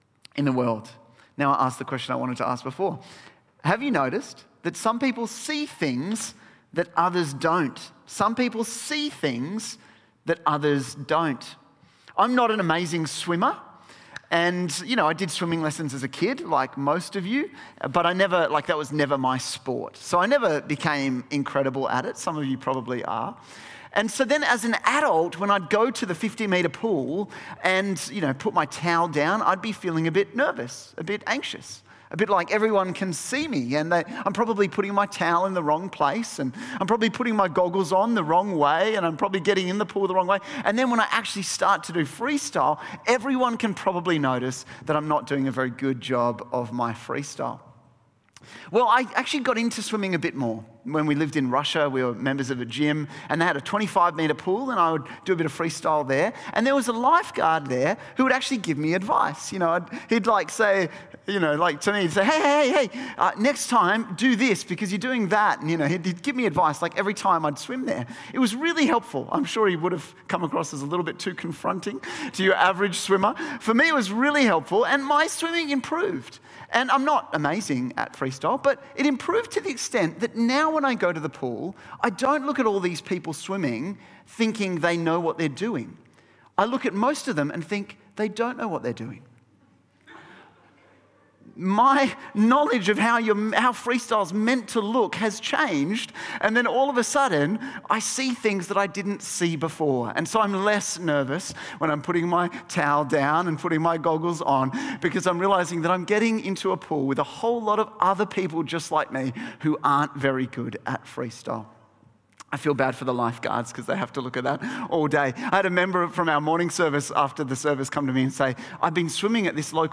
Service Type: 4PM